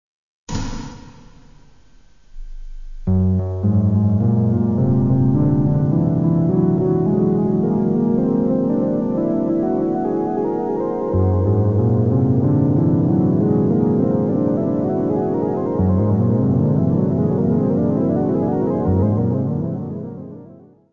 : stereo; 12 cm